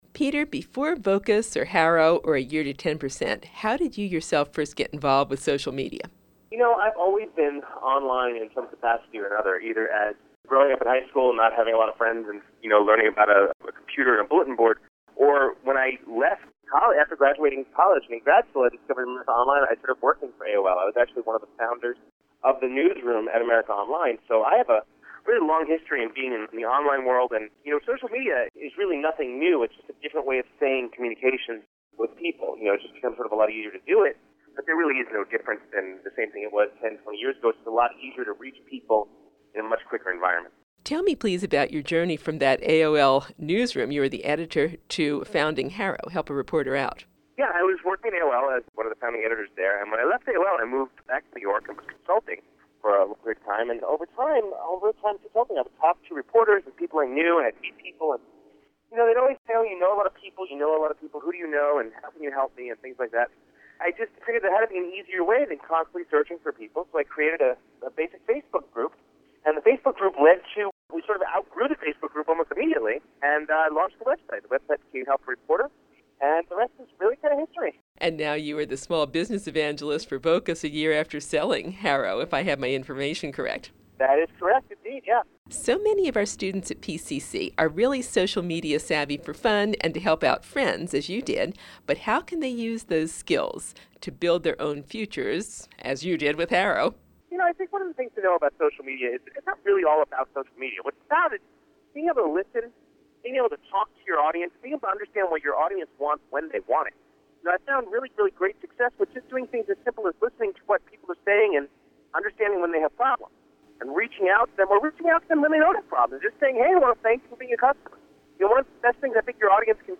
Peter Shankman interview, Pt. 1